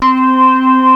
ORGAN.wav